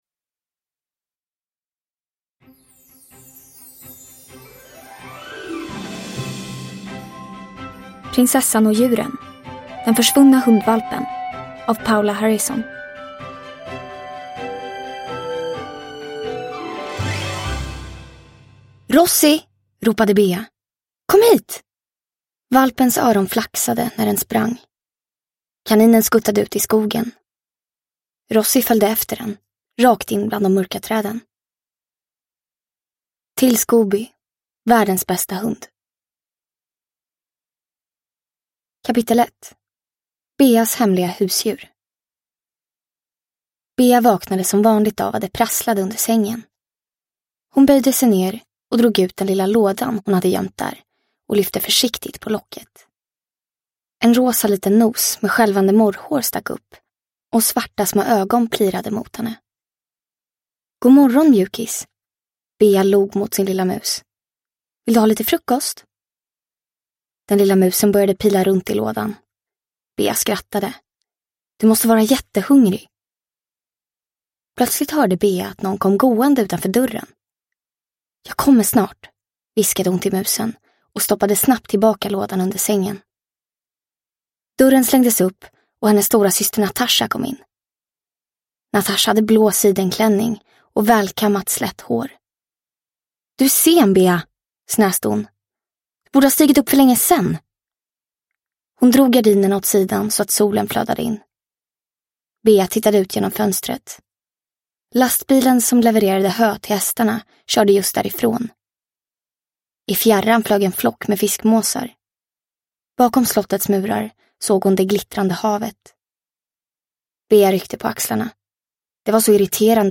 Den försvunna hundvalpen – Ljudbok – Laddas ner